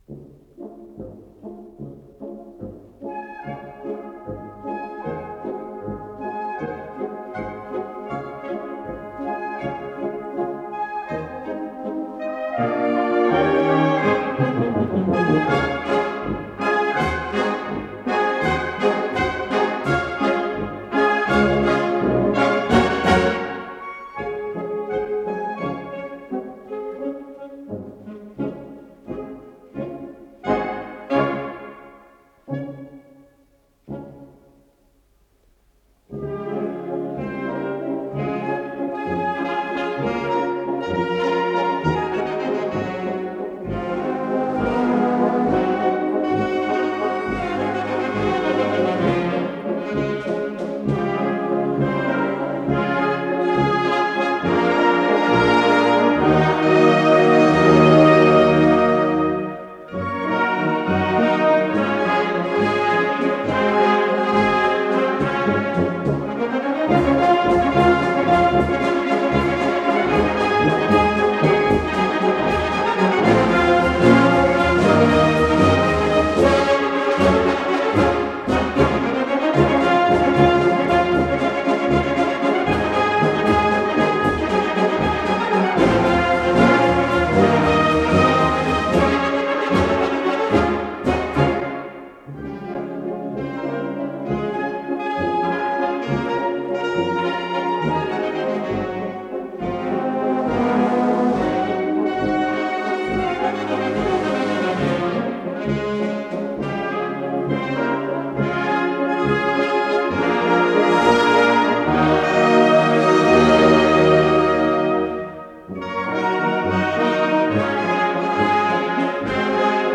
с профессиональной магнитной ленты
ИсполнителиОбразцово-показательный оркестр комендатуры Московского Кремля
ВариантДубль моно